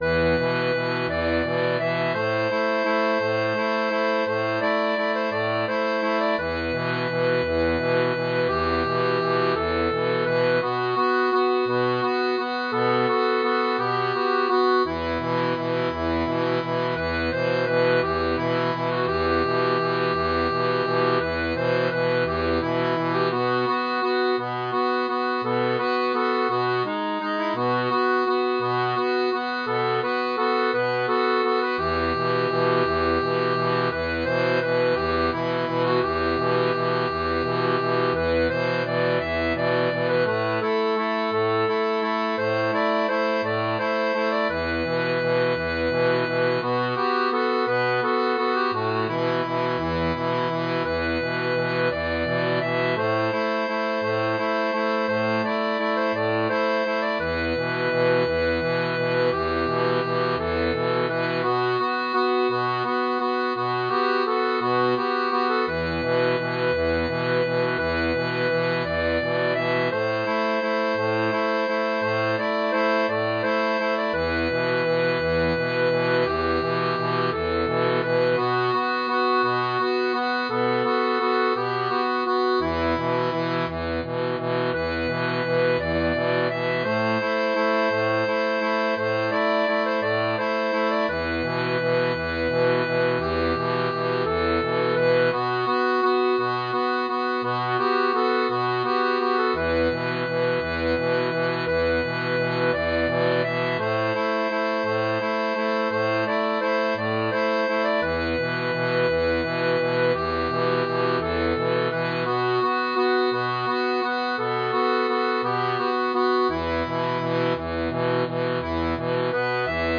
• Une tablature transposée pour diato à 3 rangs
Folk et Traditionnel